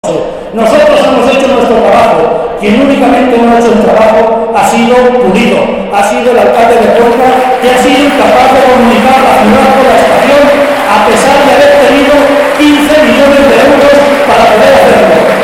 Cerca de 600 personas han acudido a la comida de Navidad que el PSOE de ha ofrecido en la capital, sin embargo, tres acto de similares características se ha celebrado también este fin de semana en Tarancón, San Clemente y Quintanar del Rey.